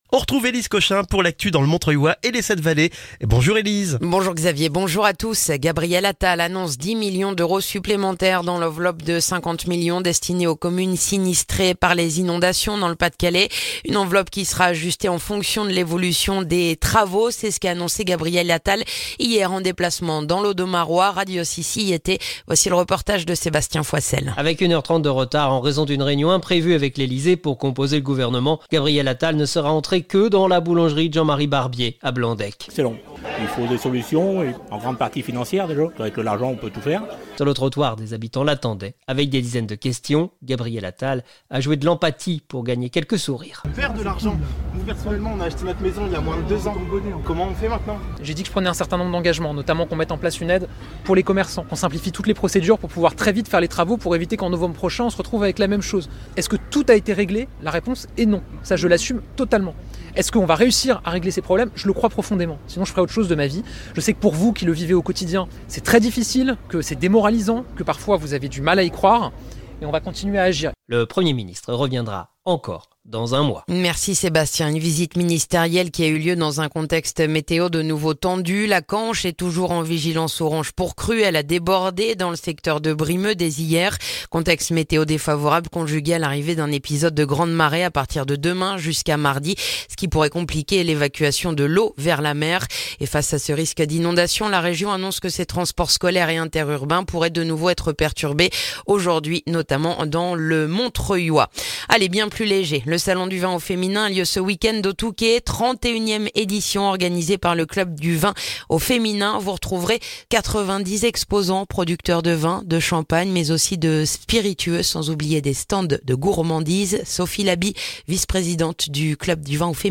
Le journal du vendredi 9 février dans le montreuillois